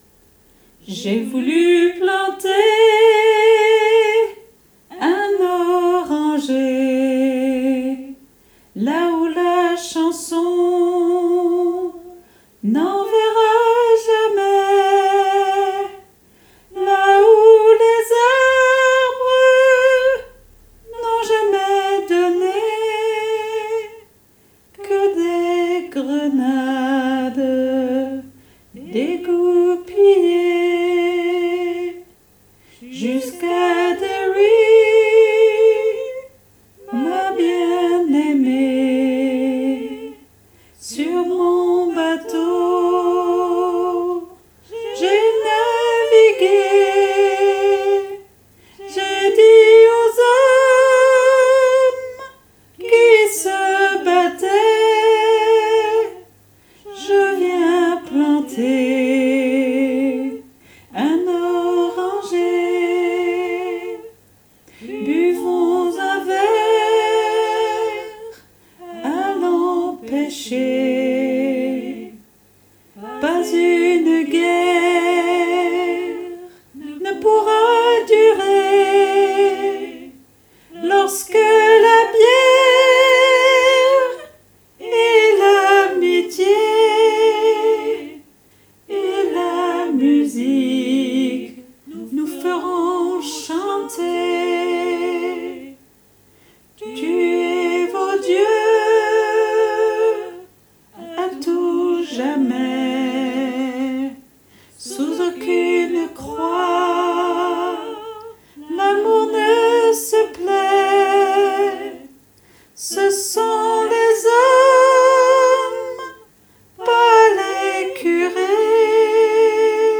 MP3 versions chantées
Soprano Et Autres Voix En Arriere Plan